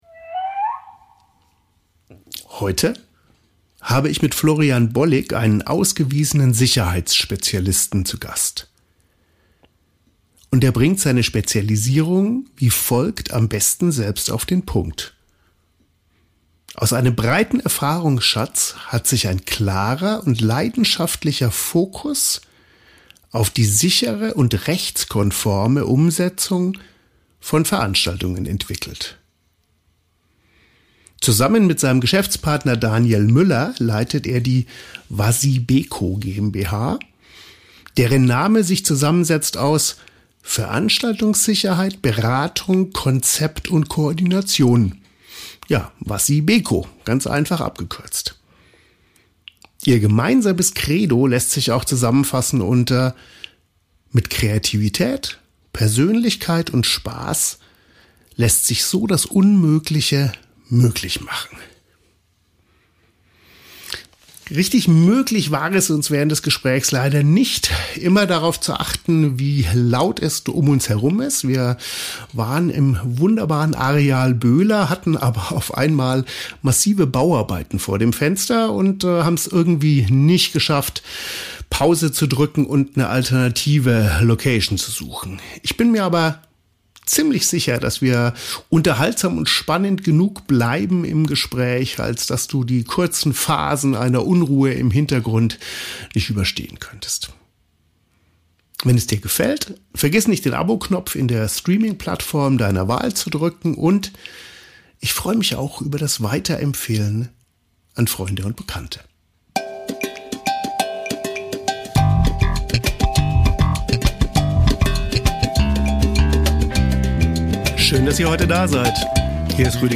Ich unterhalte mich hier mit Spezialist*Innen und Expert*Innen aus Kunst und Kultur, die Ihre Erfahrungen teilen wollen.